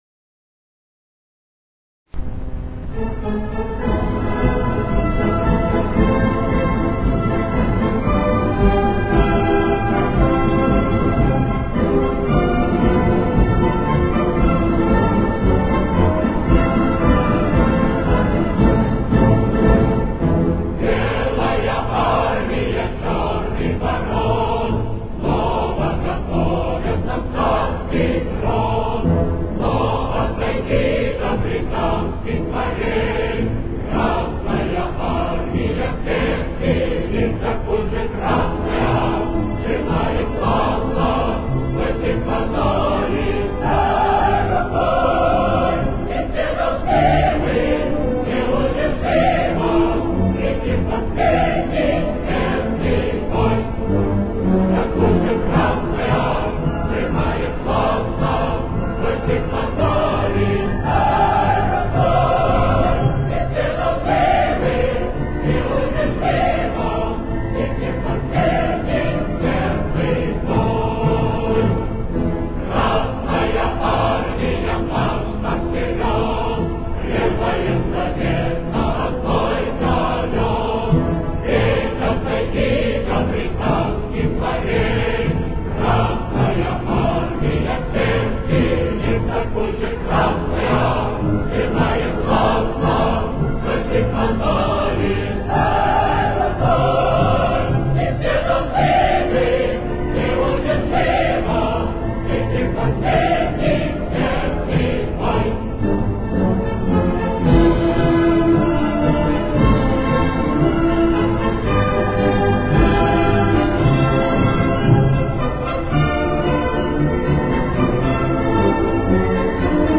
с очень низким качеством (16 – 32 кБит/с)
Темп: 115.